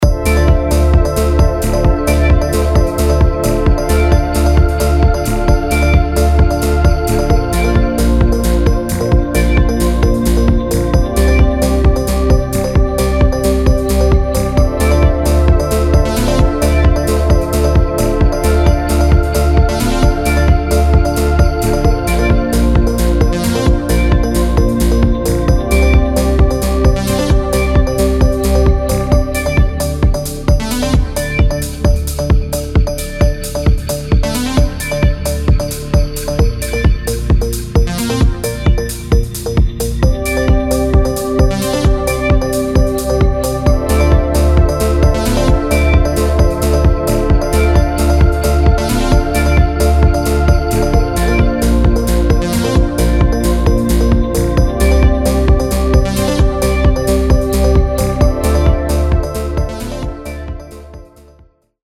EDM / Trance
Siehe Foto und mp3 Anhang anzeigen 125391 Anhang anzeigen 125393 Da bei meinem Dings hier zieht sich auch eine Spur durch, die polyrhythmisch ist und nur bei jeder 4. Zählzeit wieder auf dem 4/4 - Takt liegt.